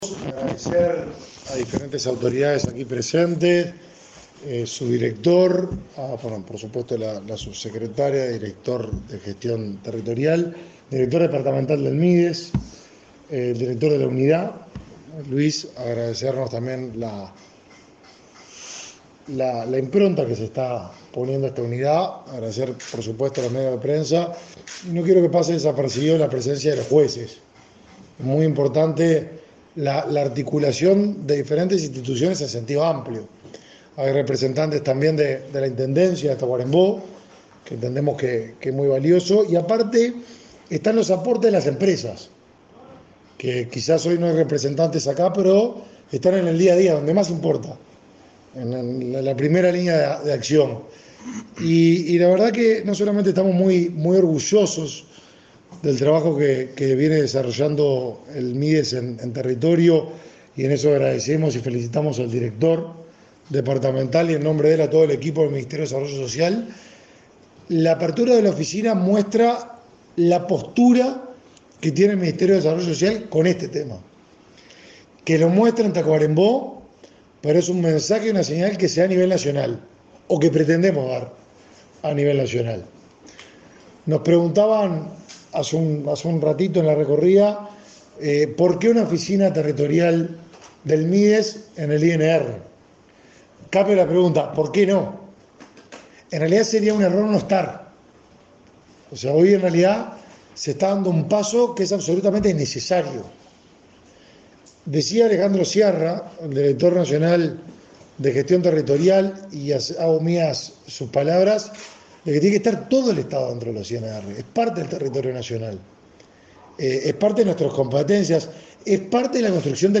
Conferencia de prensa del ministro de Desarrollo Social en Tacuarembó
El ministro de Desarrollo Social, Martín Lema, se expresó en una conferencia de prensa, en Tacuarembó, luego de inaugurar una oficina de esa cartera